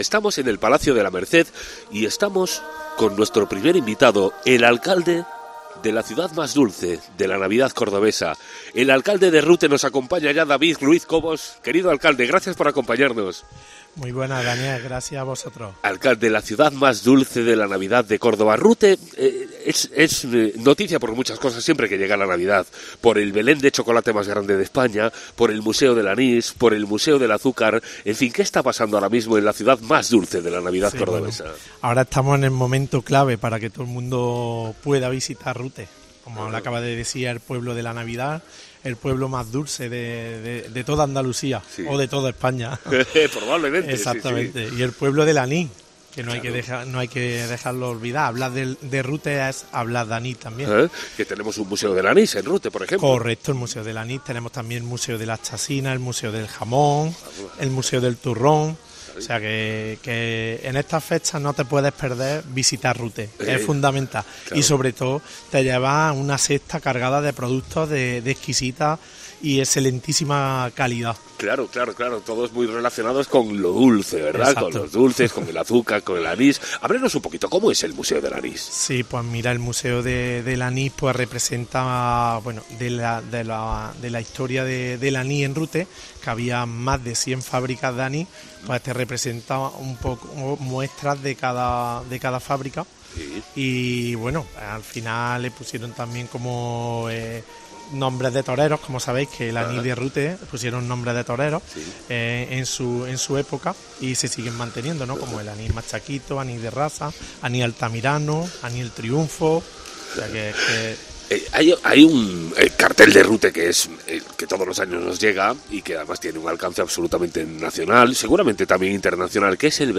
David Ruiz, alcalde de la localidad cordobesa, asegura en Cope Andalucía que ahora es el momento para visitar Rute y disfrutar de todas las actividades preparadas para las fiestas